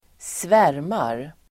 Uttal: [²sv'är:mar]